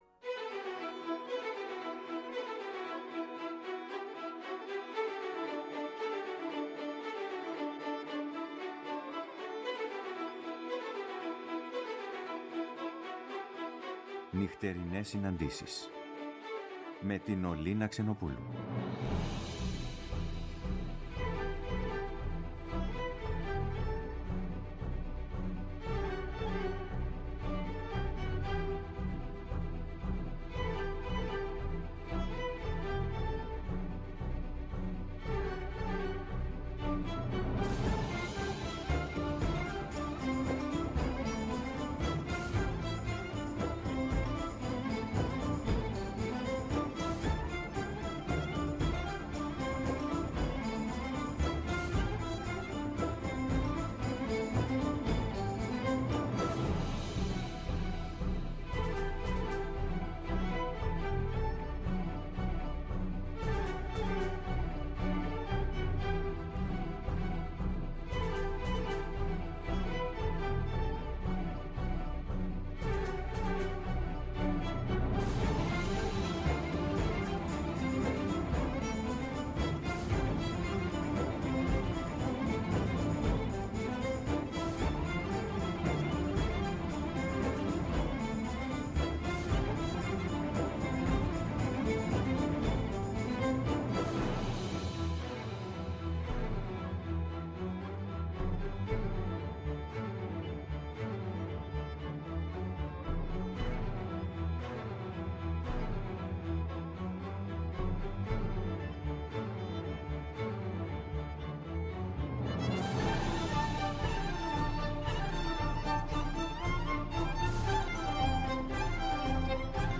Η μουσική συνοδεύει, εκφράζοντας το «ανείπωτο».